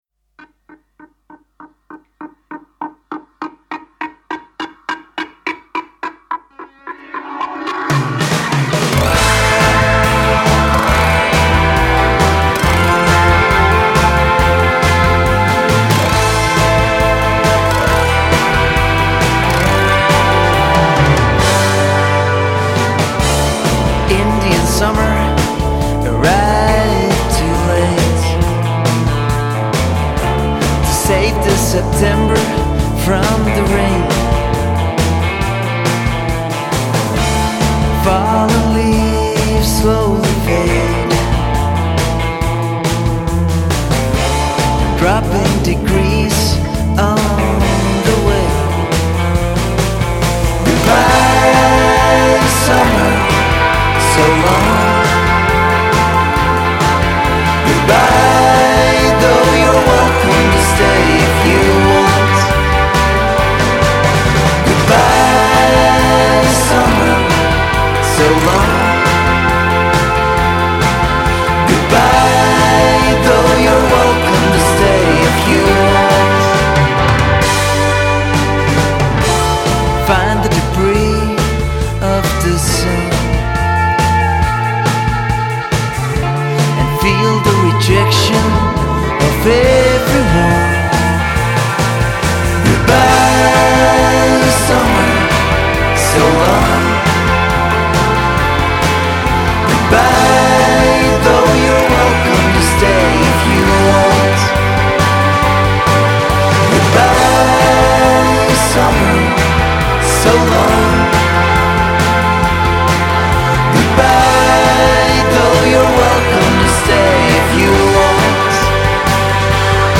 very kindly added the hippie sounding west coast harmonies.
autoharp
This is a very unique sounding performance in all.